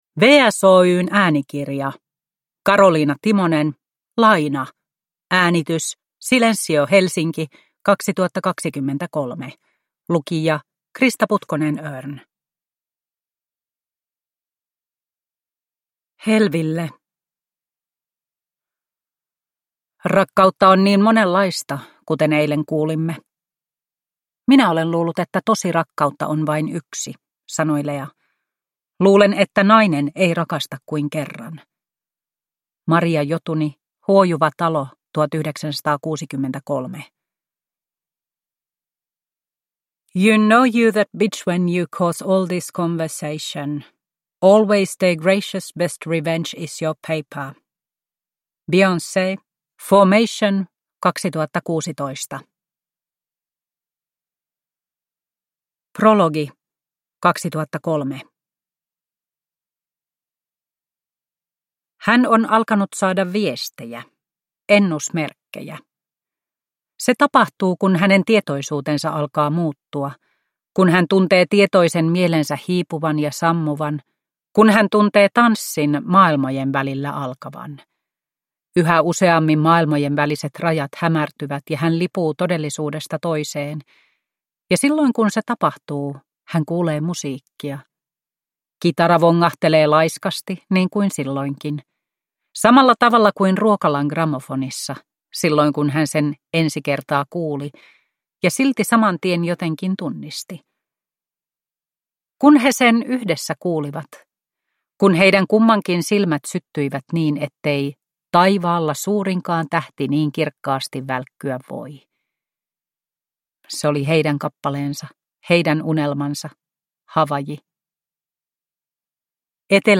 Laina – Ljudbok – Laddas ner